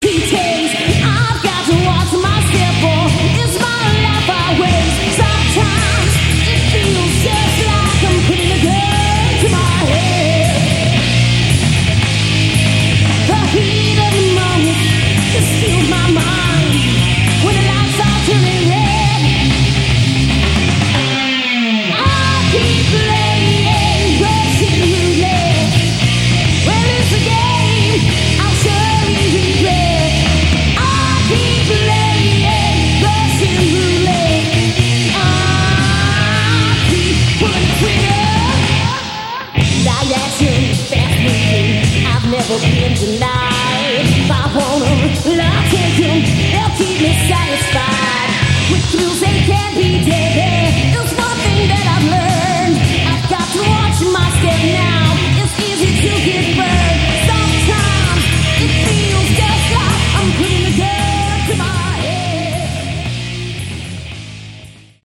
Category: Glam/Hard Rock